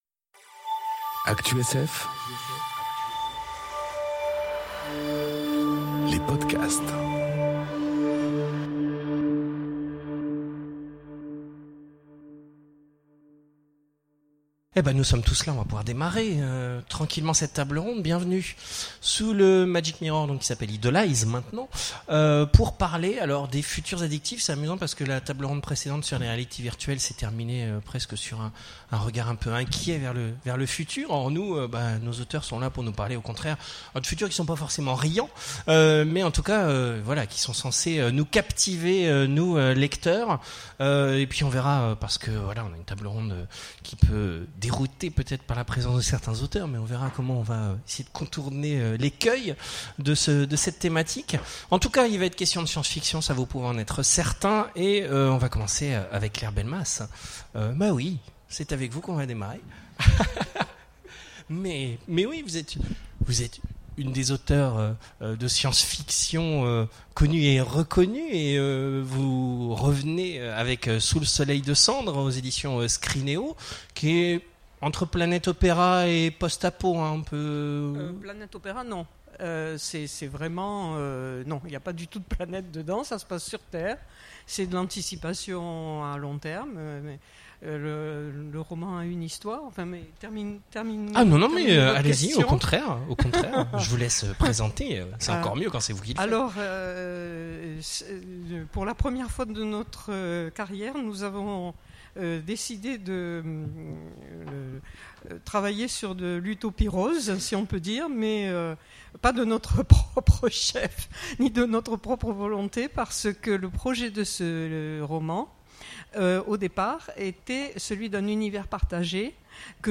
Imaginales 2018 : Conférence Romans de science-fiction... Futurs addictifs